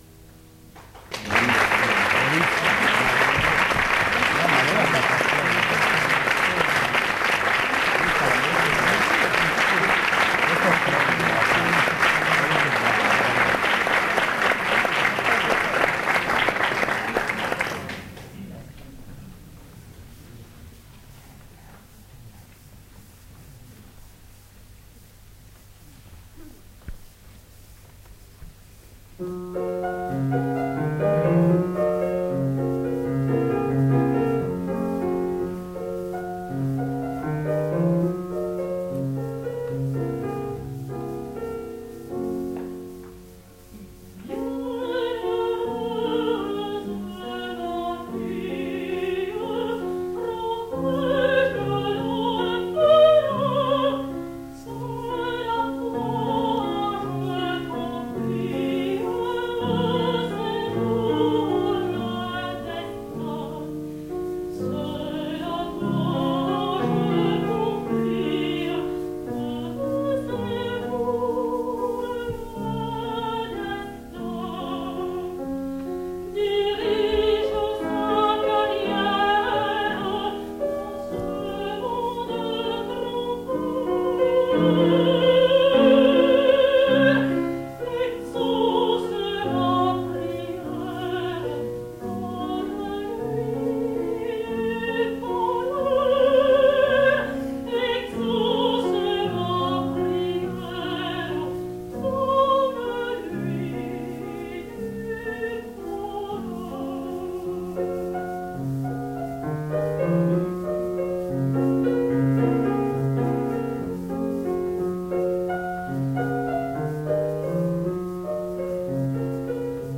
Martine Dupuy, mezzosoprano
pianoforte
Roma, Teatro dei Satiri